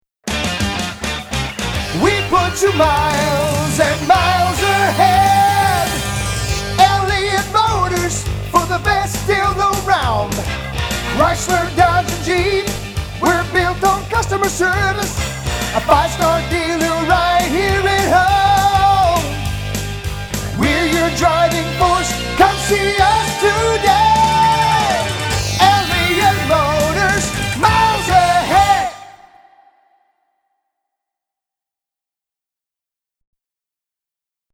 National quality jingles at competitive prices!